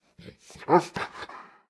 pdog_idle_3.ogg